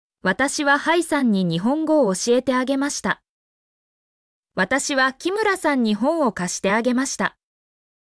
mp3-output-ttsfreedotcom-61_mksjEmi4.mp3